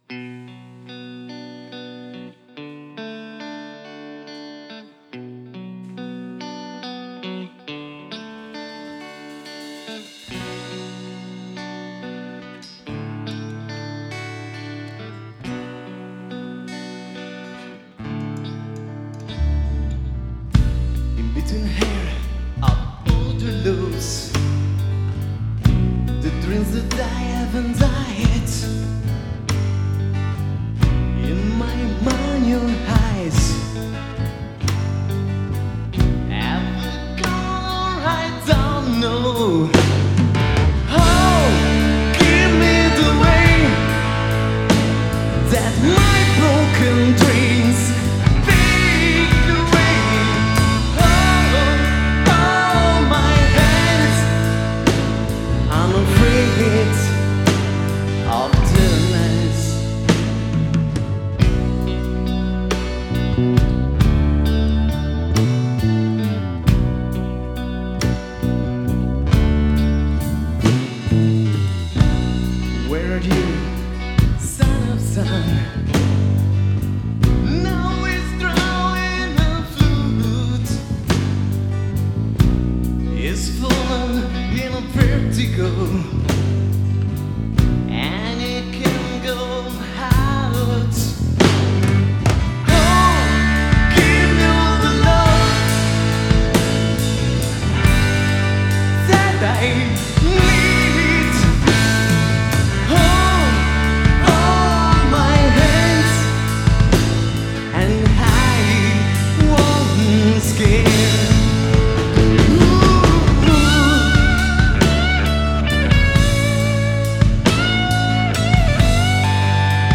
live a Utri Beach (Genova)
Chitarra Solista e voce
Chitarra Ritmica
Tastiere e voce
Basso e voce
Batteria